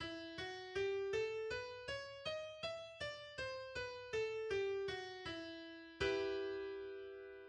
e-Moll
Melodisch: e - fis ^ g - a - h - cis - dis ^ e
Notenbeispiel Tonleiter im melodischen Moll und Grundakkord: